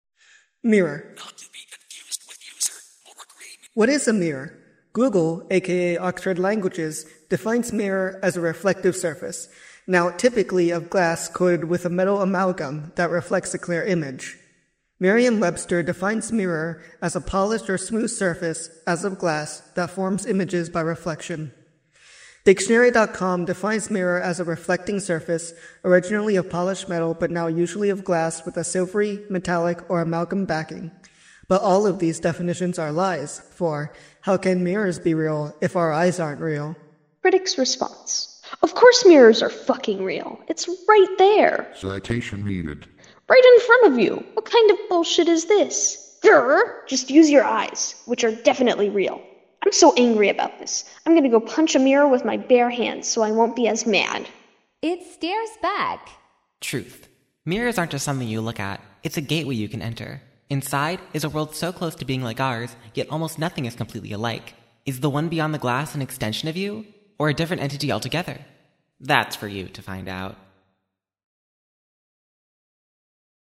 Audiobook
MirrorAudiobook.mp3